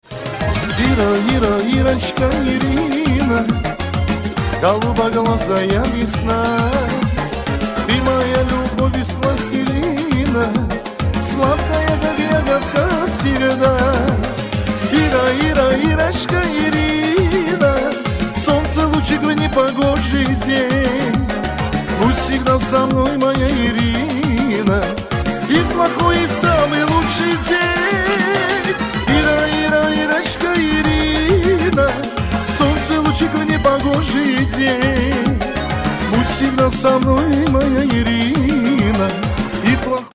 мужской вокал